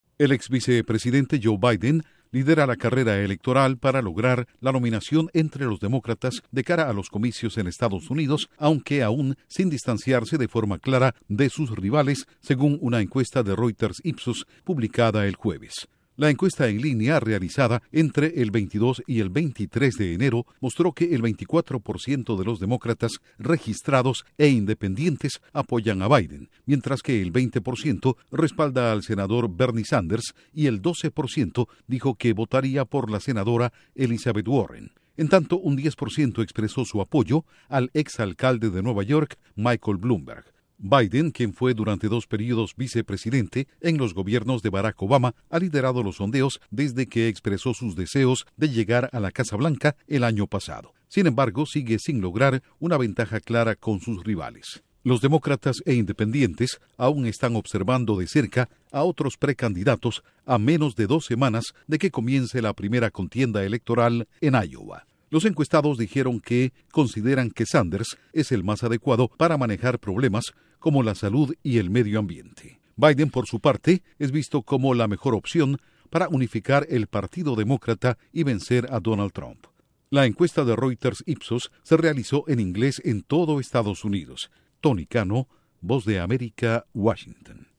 Sondeo: Biden lidera carrera electoral demócrata de cara a presidenciales en Estados Unidos. Informa desde la Voz de América en Washington